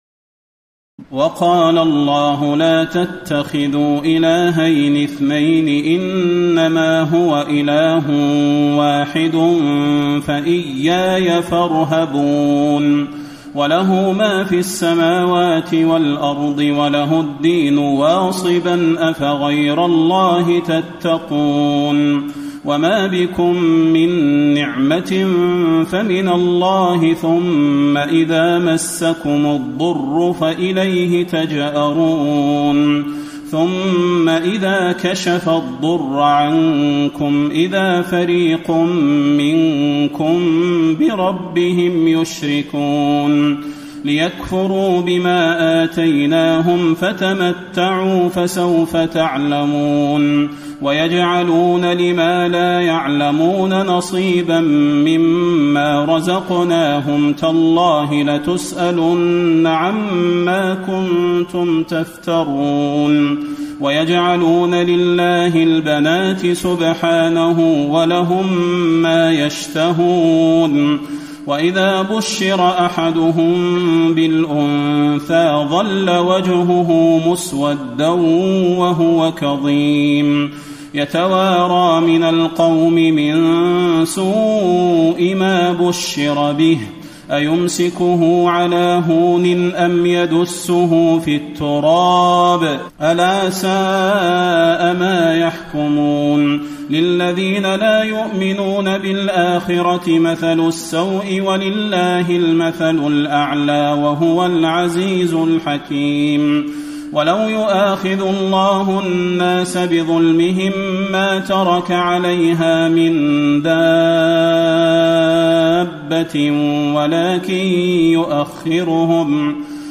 تراويح الليلة الرابعة عشر رمضان 1434هـ من سورة النحل (53-128) Taraweeh 14 st night Ramadan 1434H from Surah An-Nahl > تراويح الحرم النبوي عام 1434 🕌 > التراويح - تلاوات الحرمين